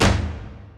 Tribal Snare.wav